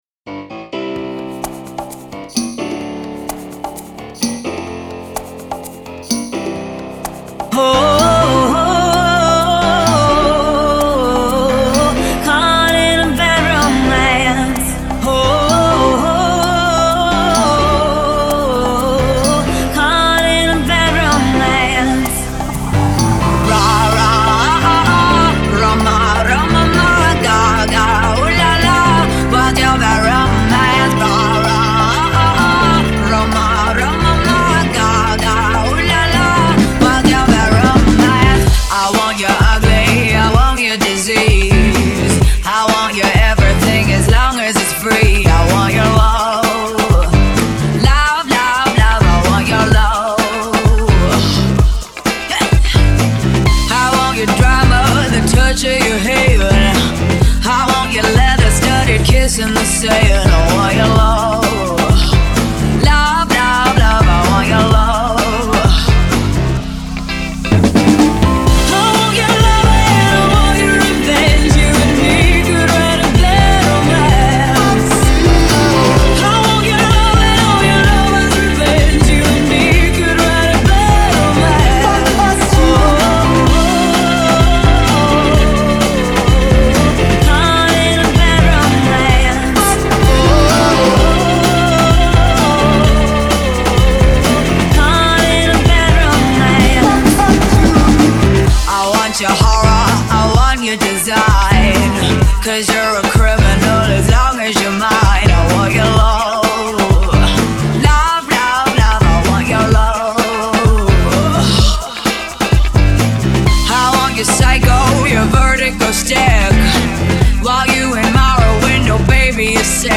Mashup
sassy, soulful, rump-shaking